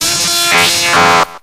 Audio / SE / Cries / ELECTABUZZ.ogg